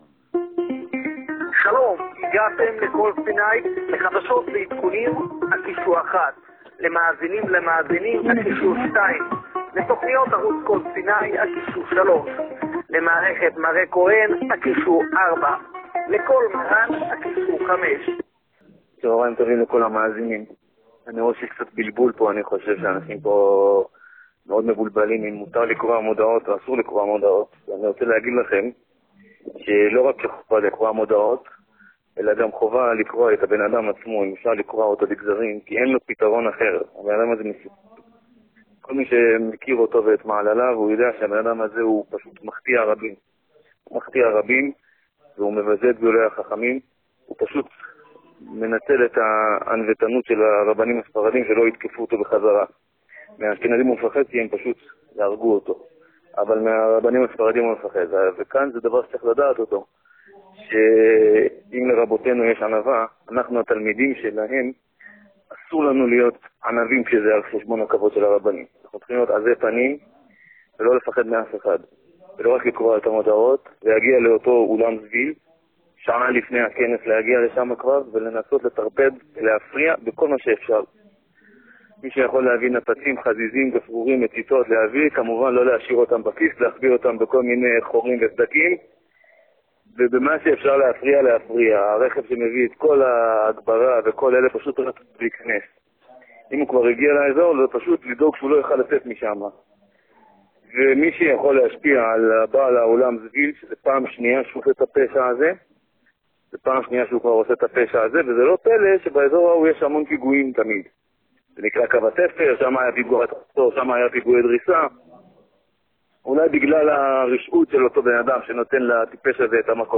הודעה שהושמעה ב'קול סיני'